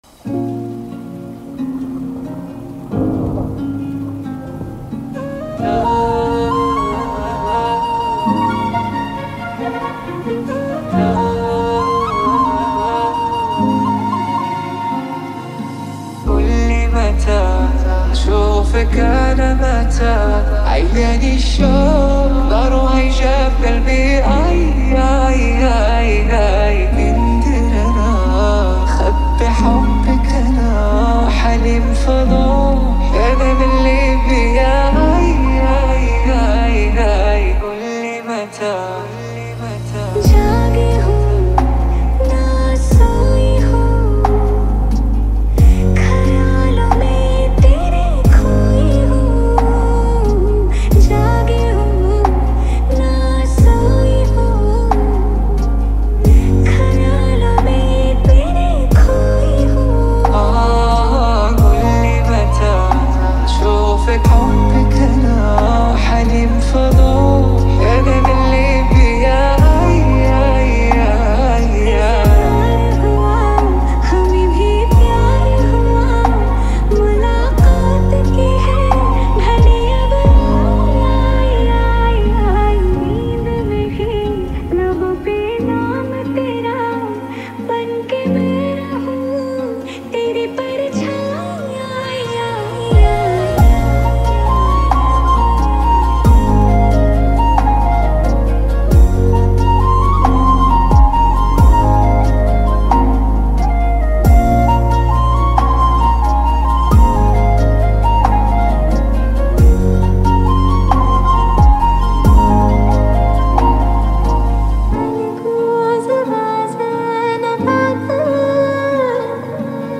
میکس LoFi